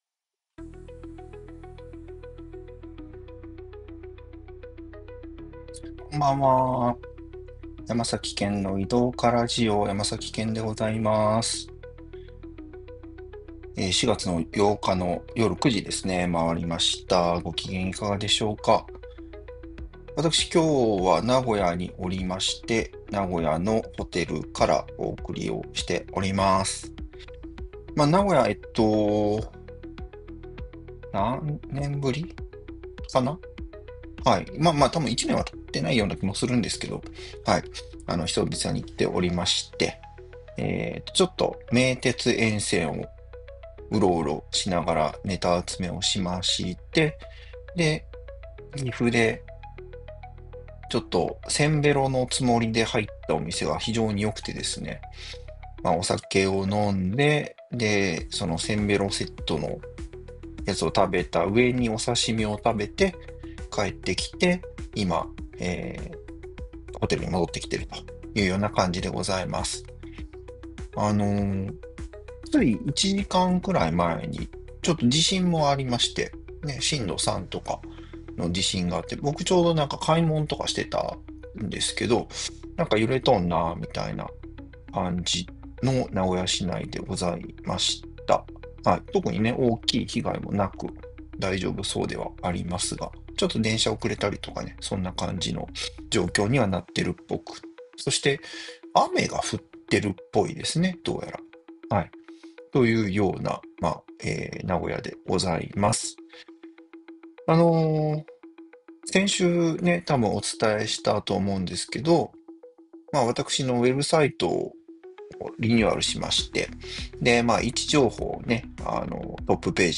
今回は名古屋からお送りしました！